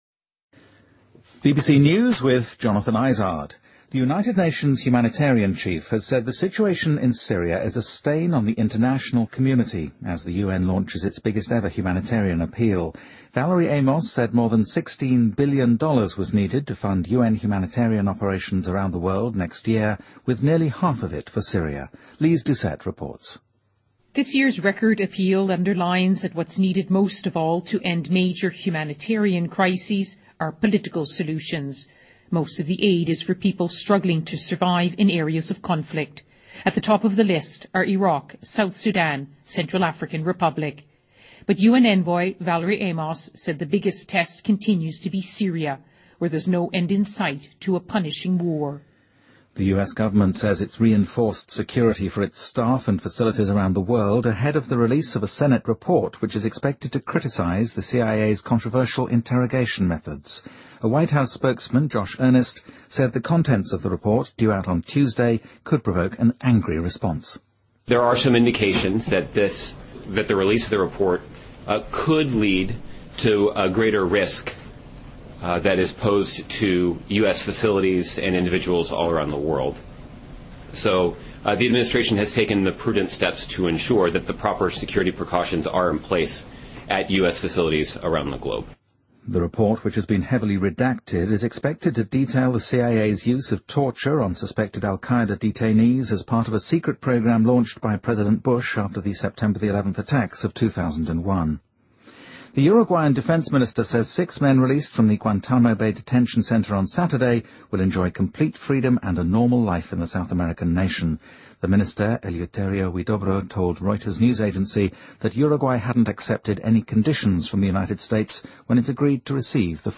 BBC news,世卫组织称塞拉利昂成为埃博拉患者人数最多的国家